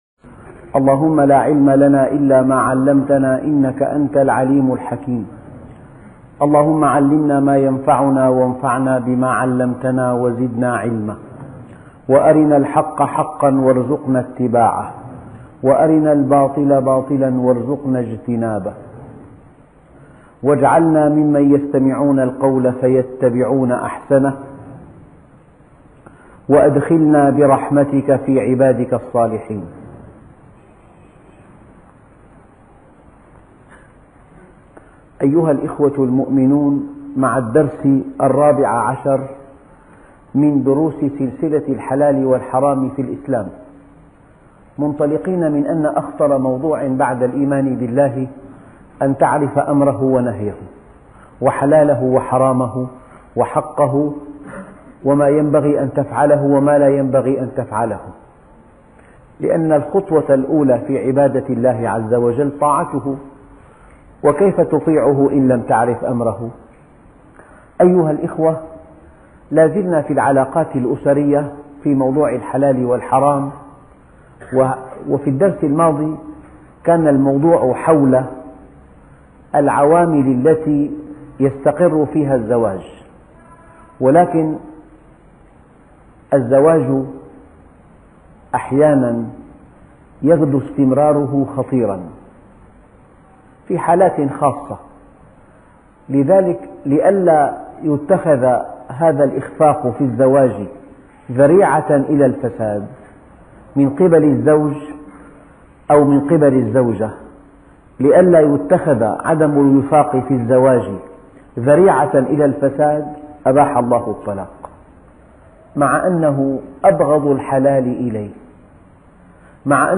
الدرس 14- الطلاق -الفقه الإسلامي عن الحلال والحرام - الشيخ محمد راتب النابلسي